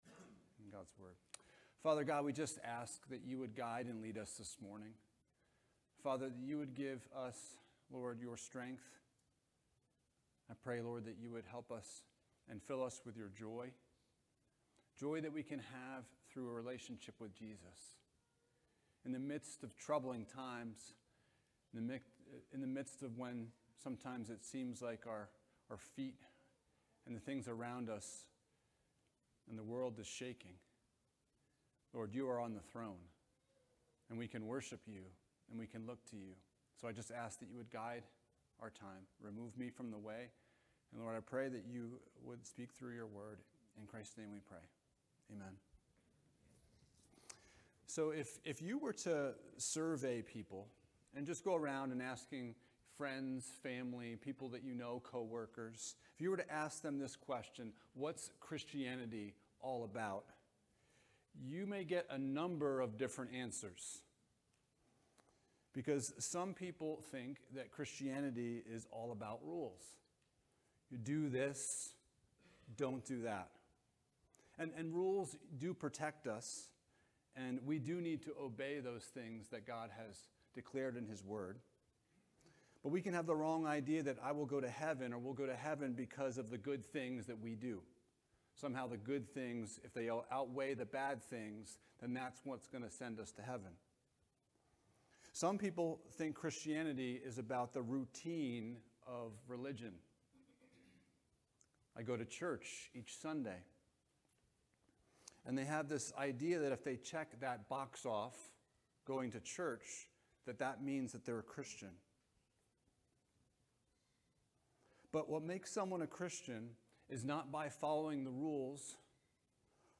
Renovation of the Heart Passage: Nehemiah 8:9 - 9:8 Service Type: Sunday Morning « Contending for the Truth What is Your Worldview?